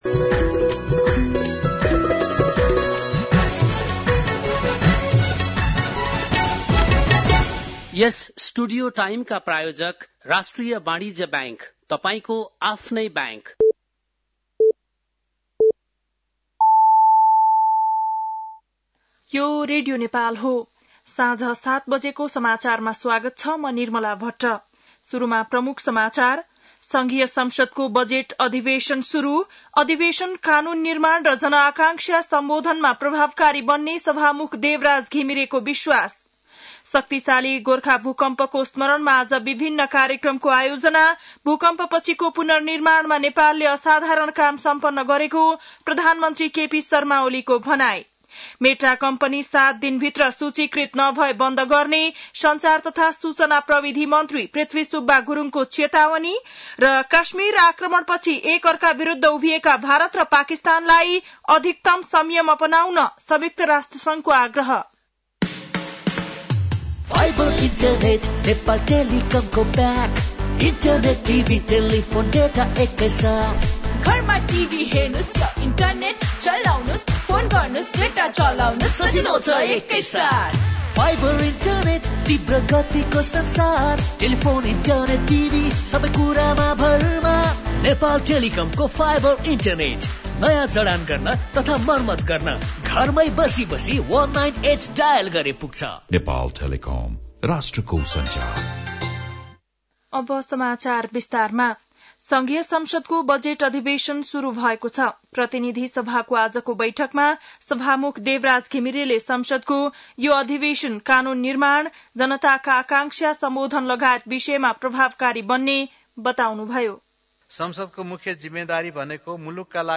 An online outlet of Nepal's national radio broadcaster
बेलुकी ७ बजेको नेपाली समाचार : १२ वैशाख , २०८२